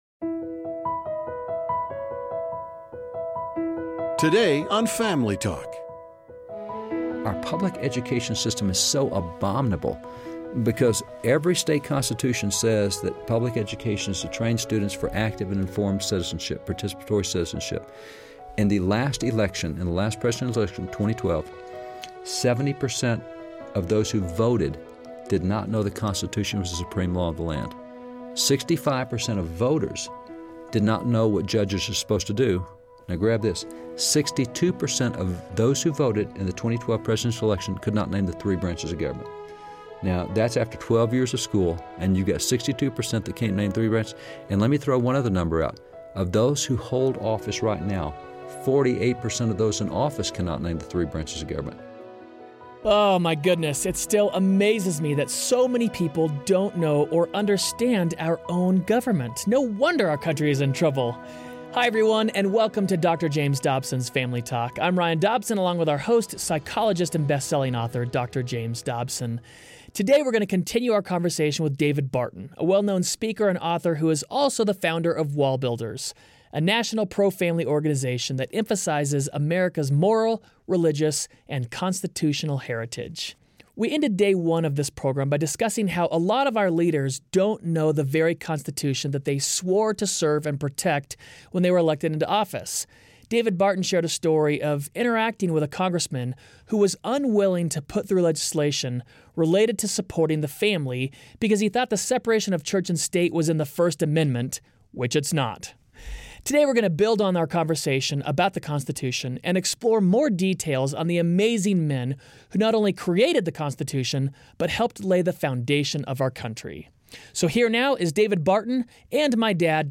Americas religious history is both rich and pluralistic, yet seems to be founded on Christian principles. On the next classic edition of Family Talk, Dr. James Dobson interview David Barton on the religious leanings of our founding fathers.